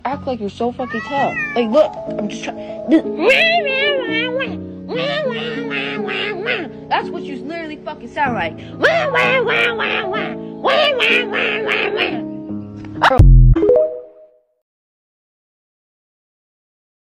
wa wa wa thats what u sound like Meme Sound Effect
This sound is perfect for adding humor, surprise, or dramatic timing to your content.
wa wa wa thats what u sound like.mp3